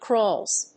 /krɔlz(米国英語), krɔ:lz(英国英語)/